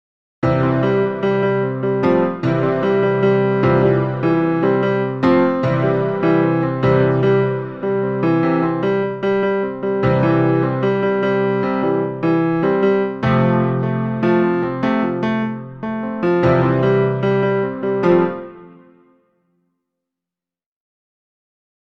Zunächst ist das Ausganspattern zu hören, danach dann die Variationen, welche mit ChordPotion erzeugt wurden.
Piano:
chordpotion-piano-2.mp3